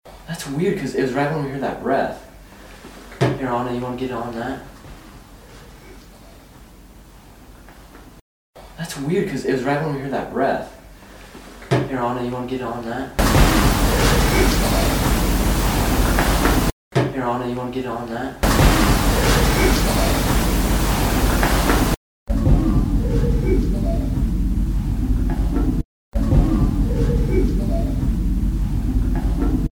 Immediately following the breath, I had my recorder running and caught what sounds like a breath again and a voice saying "it's cold".shin
Sitting Bull Voice (MP3) - The original clip is first, followed by a repeat with amplified audio.